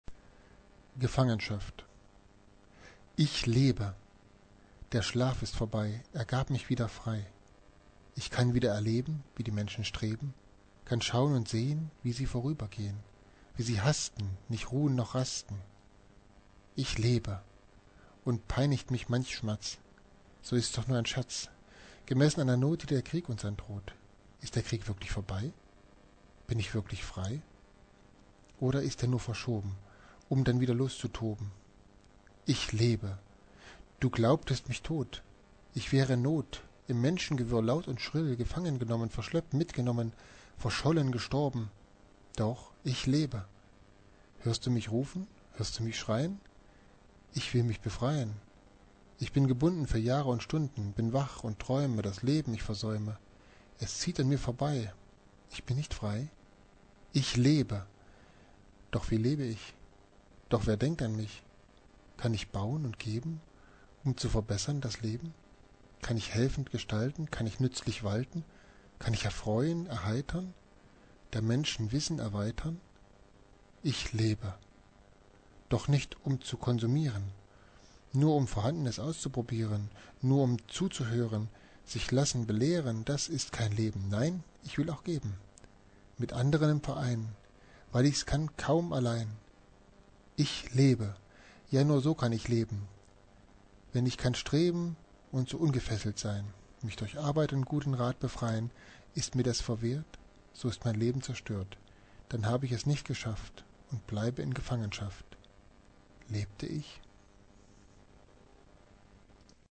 Gedicht als MP3-Sounddatei